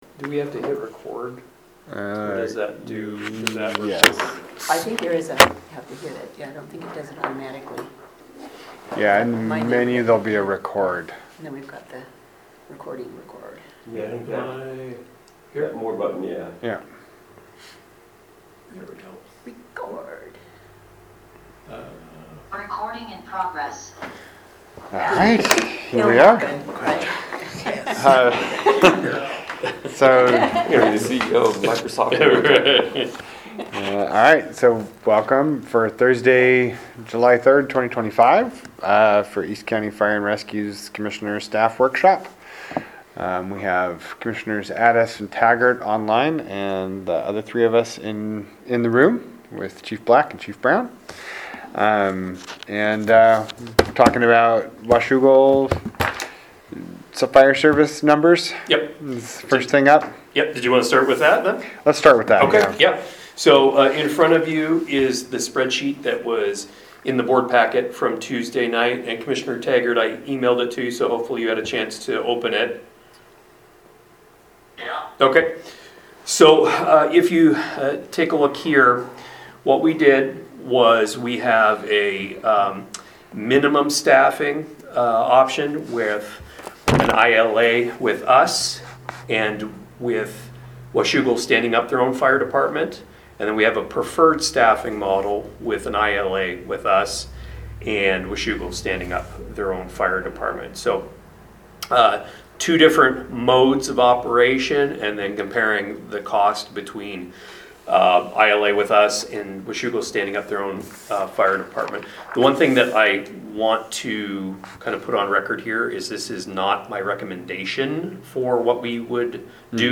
Commissioner Staff Workshop Meeting